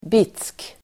Uttal: [bit:sk]